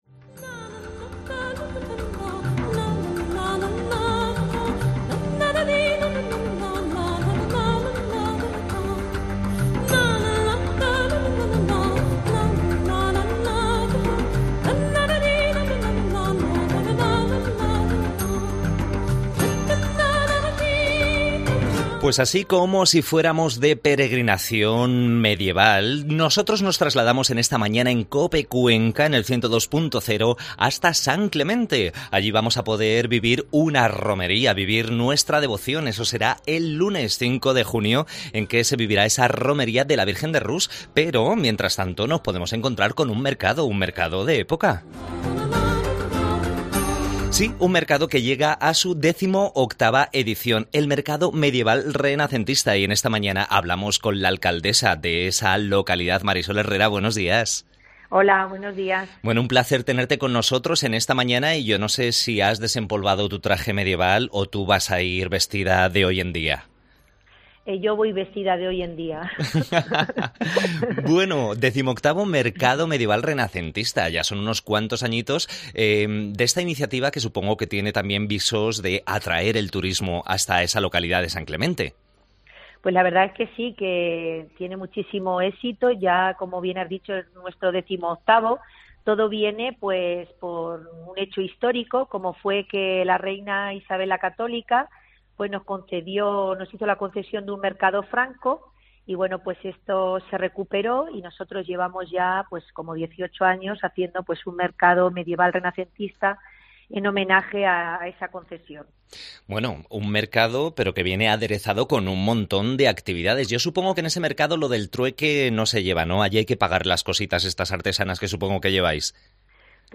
Entrevistamos a la alcaldesa de la localidad, Mª Sol Herrero.